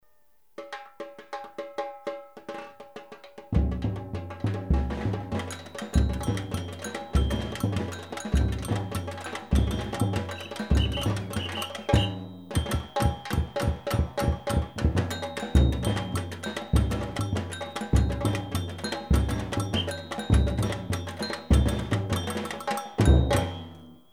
Site d'audios et de partitions sur les percussions br�siliennes jou�es dans une batucada.
Break 9 coups en l air